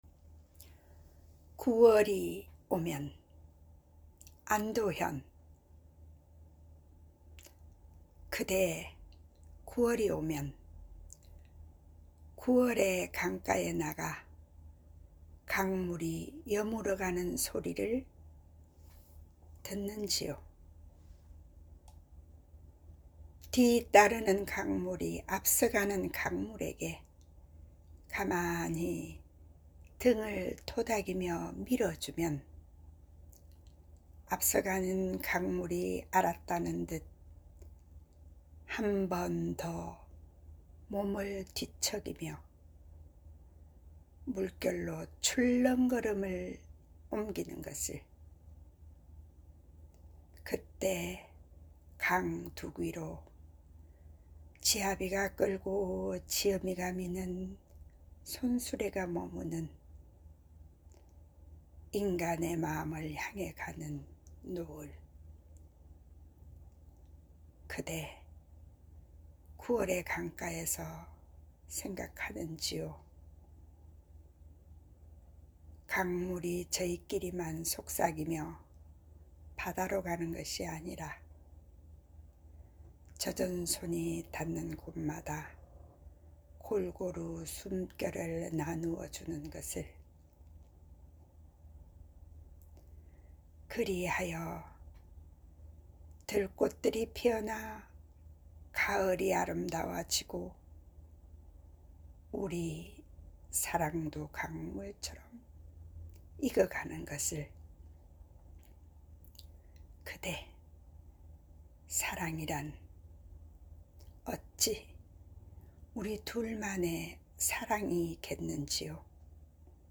(11) [詩낭송] 9월이 오면/안도현
▷ AI 도움 없이 낭송해 본 '9월이 오면'
9월이 오면-낭송.mp3